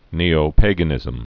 (nēō-pāgə-nĭzəm)